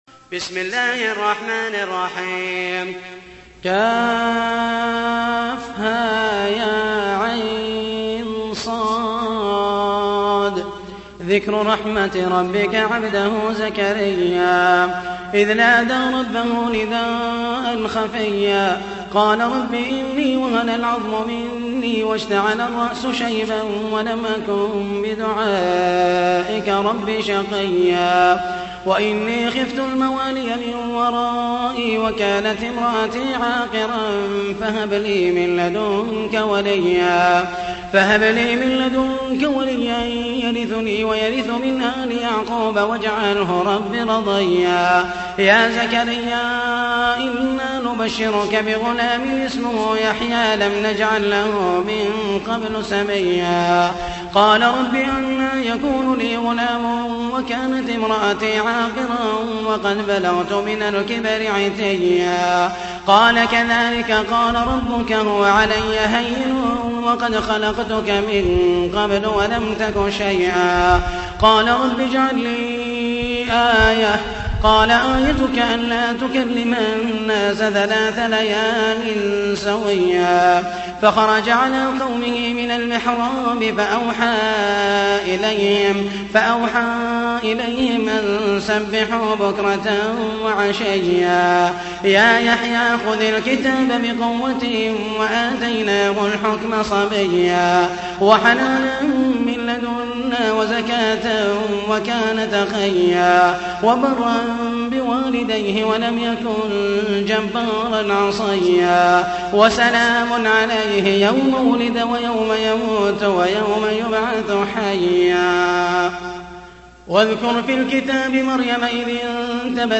تحميل : 19. سورة مريم / القارئ محمد المحيسني / القرآن الكريم / موقع يا حسين